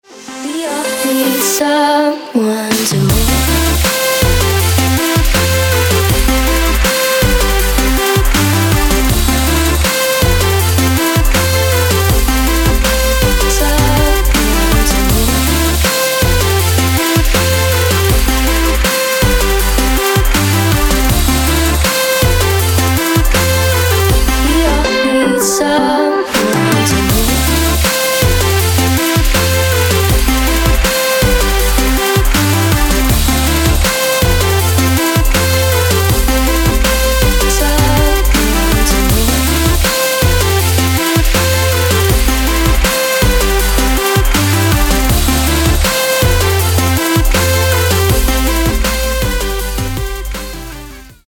• Качество: 160, Stereo
Electronic
club
electro